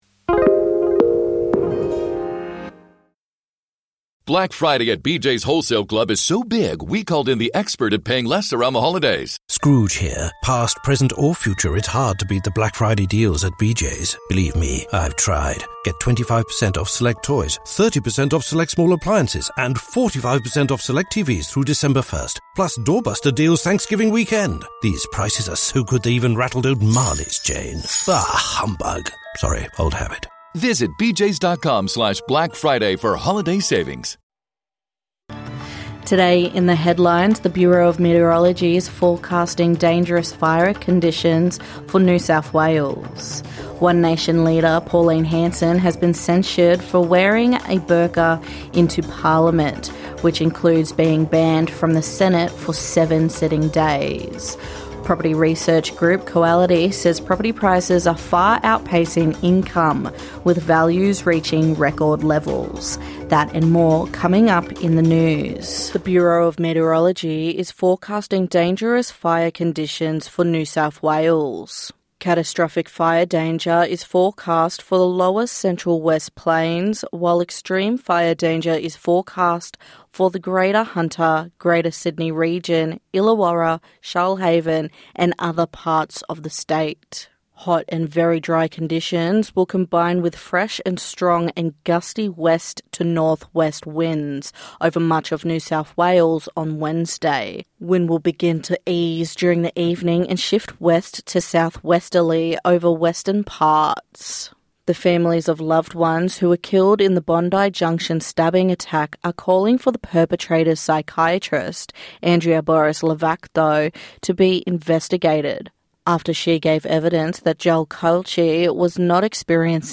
NITV Radio bring stories from across the country and in community.